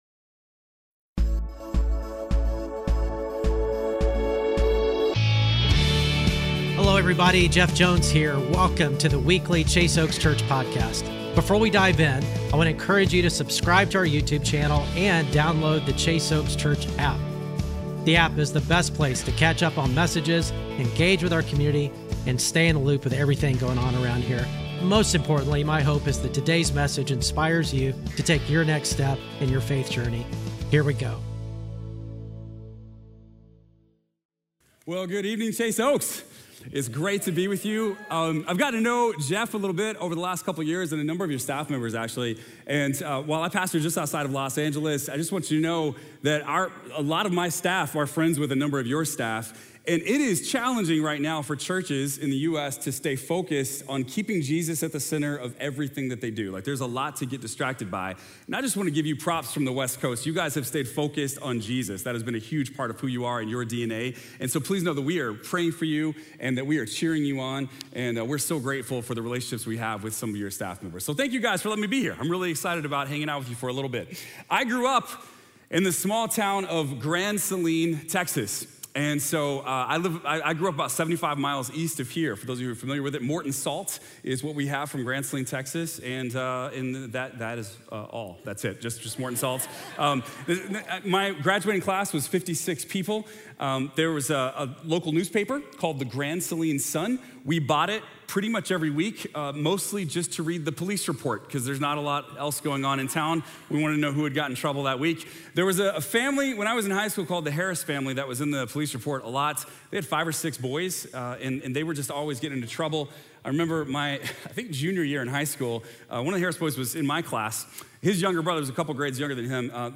Weekly Sermons at Chase Oaks Church in Plano, Texas